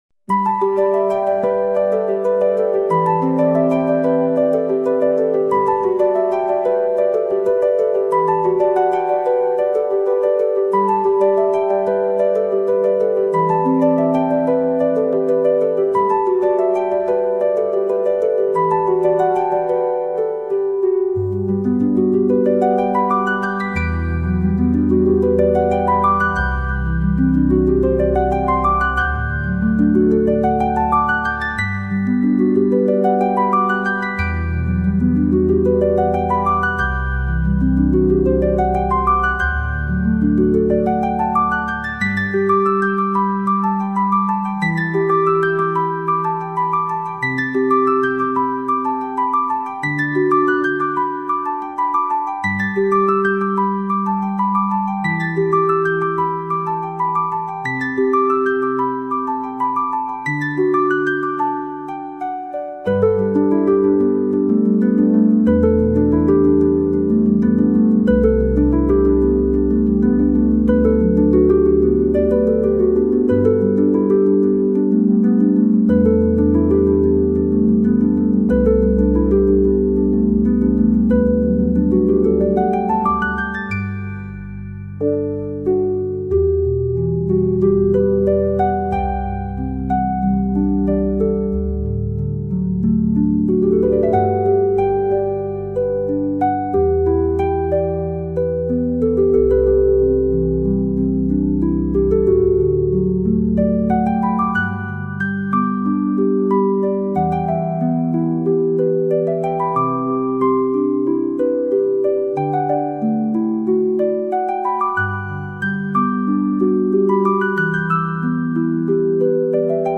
" a mesmerizing and abstract piano piece.